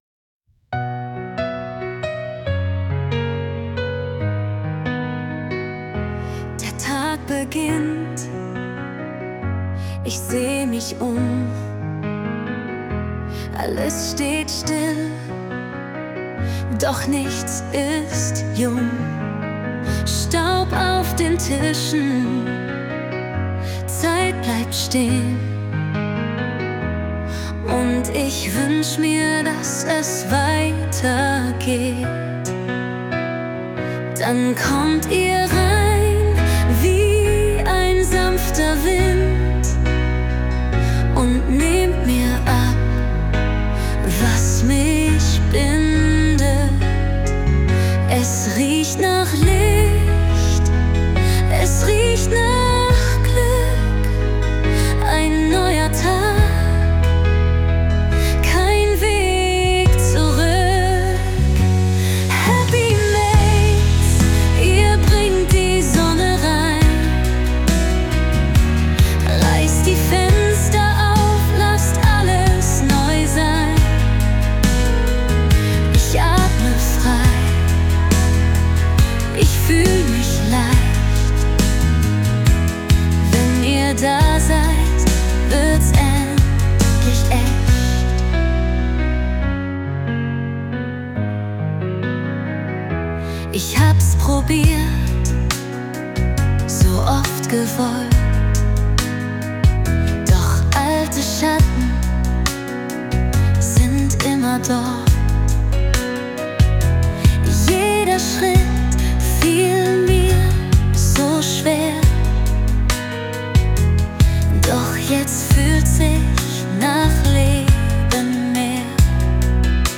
Ein paar Klicks und innerhalb von zwei Minuten ist ein toller Song entstanden, der die Gefühle die mit einer Reinigung durch HappyMaids verbunden sind bestens präsentiert.